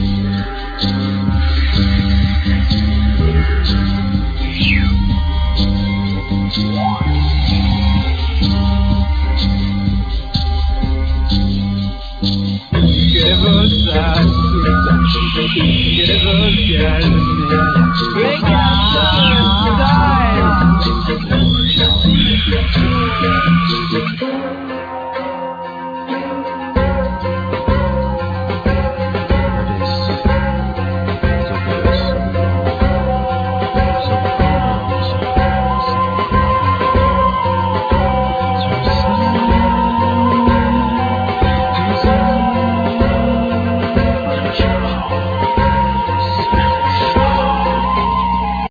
Bass-gitar
Drums,Percussions
Voice,Sampler,Djembe
Clarinet
Mong guitar